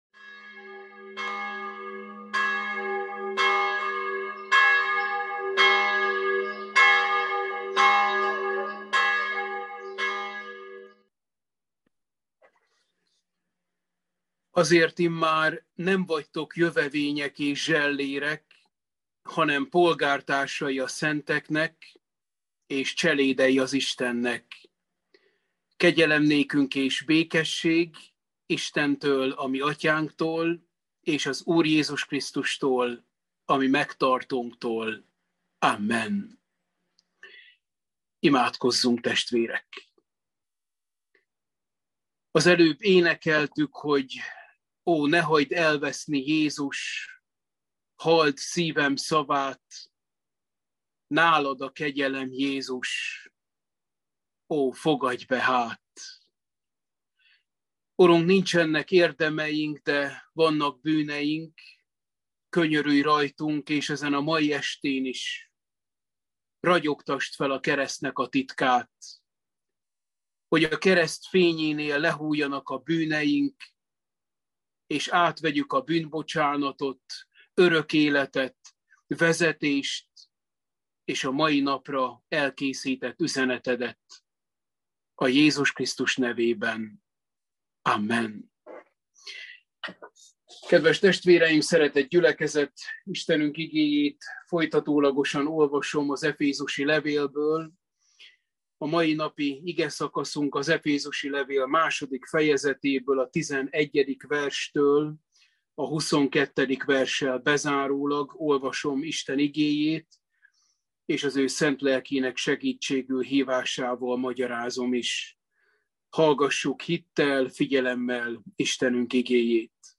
Efézusi levél – Bibliaóra 5